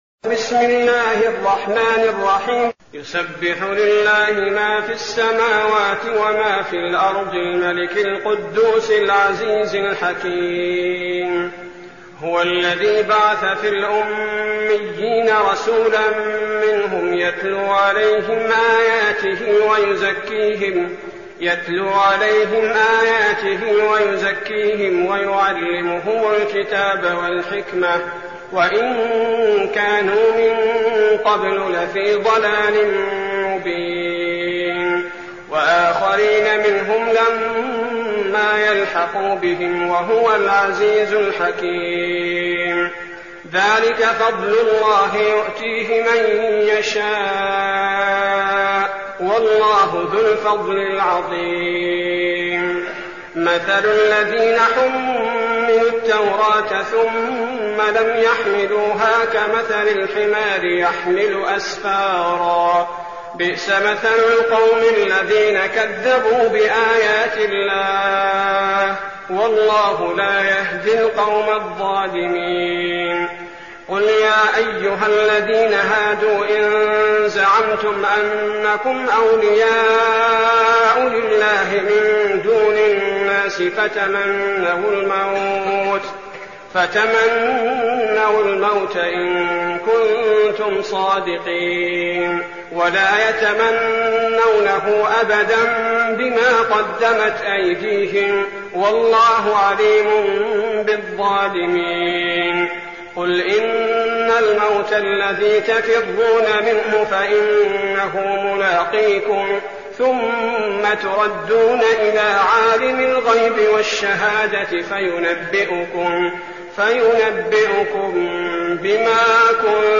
المكان: المسجد النبوي الشيخ: فضيلة الشيخ عبدالباري الثبيتي فضيلة الشيخ عبدالباري الثبيتي الجمعة